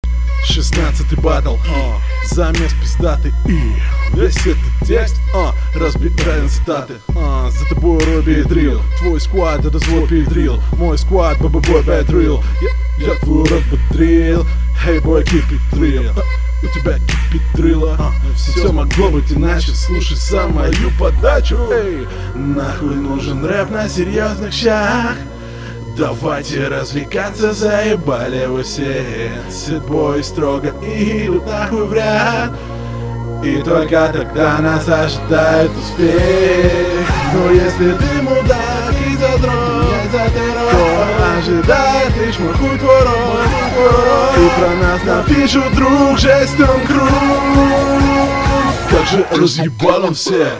Есть определенное разнообразие в технике, но ты откровенно перекривлялся и переюмоорил - неудачно.